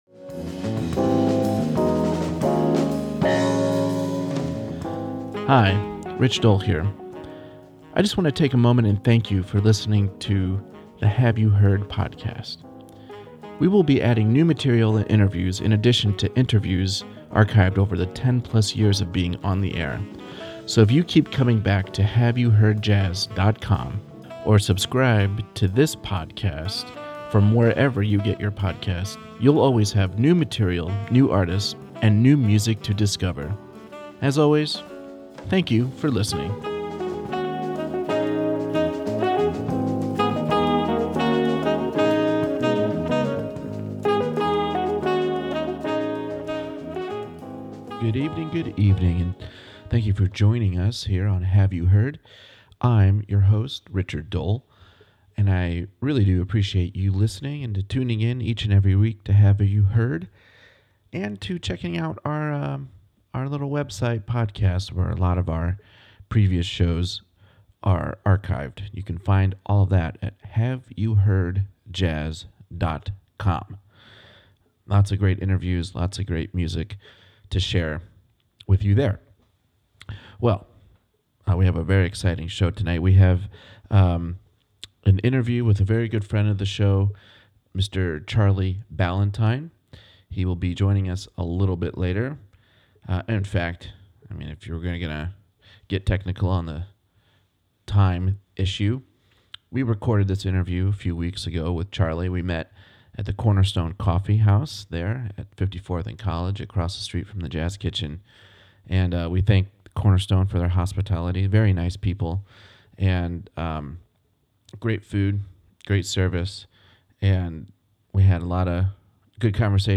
We hope you enjoy our conversation